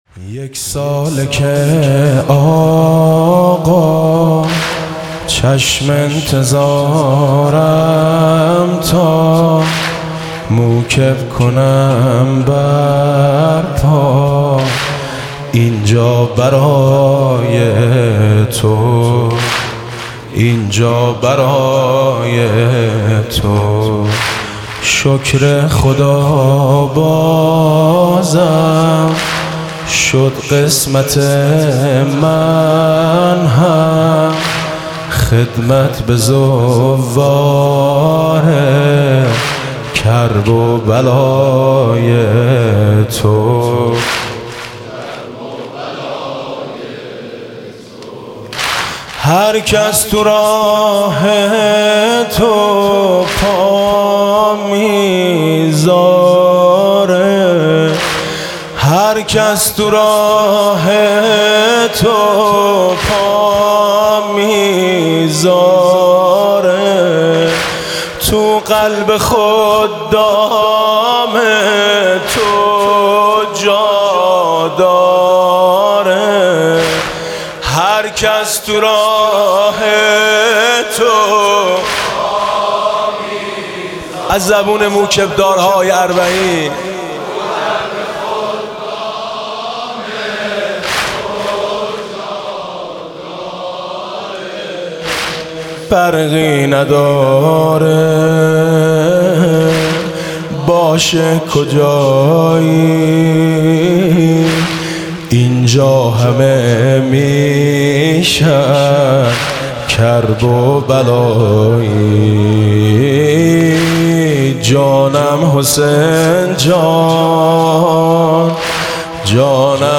مداحی فارسی و عربی از زبان خادمان مواکب حسینی در اربعین
فایل صوتی "مداحی فارسی و عربی" از زبان خادمان مواکب حسینی در اربعین، با نوای حاج میثم مطیعی که مورخ 95/06/04 در هیئت شهدای گمنام اجرا شده است، در ادامه قابل دریافت می باشد.